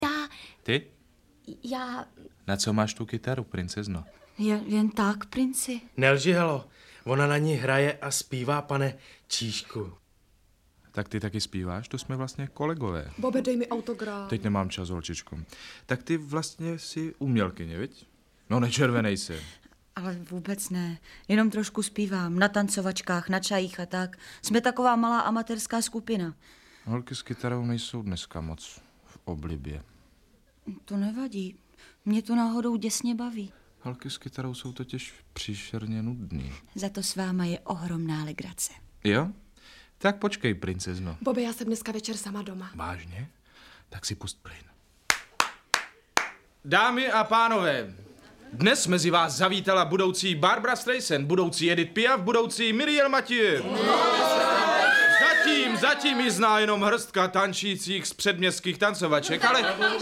Audiobook
Read: Jan Kanyza